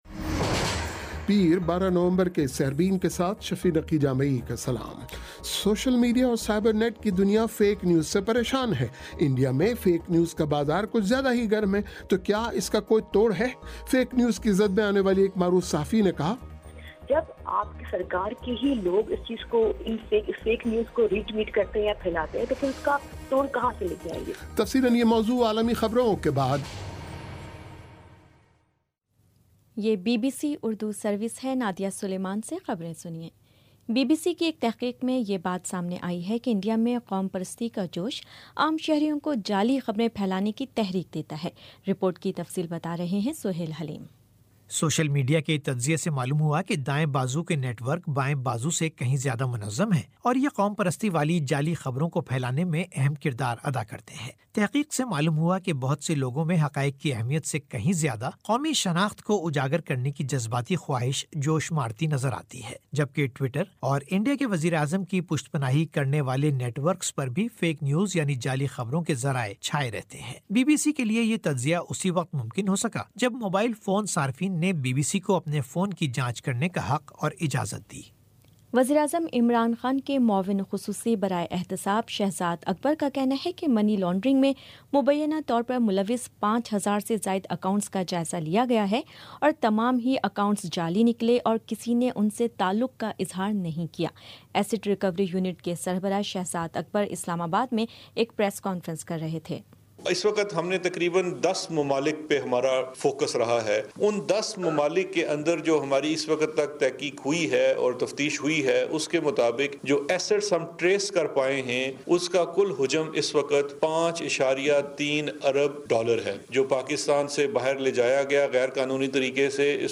پیر 12 نومبر کا سیربین ریڈیو پروگرام